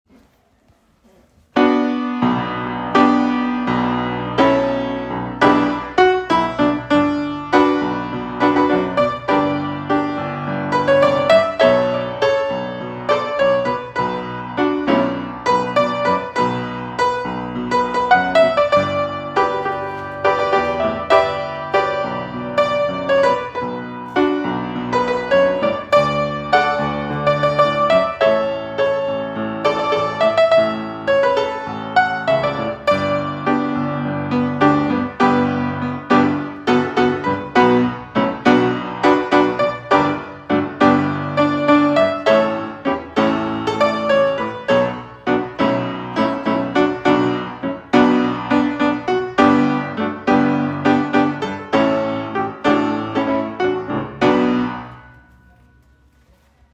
Audition Song Backing Track: (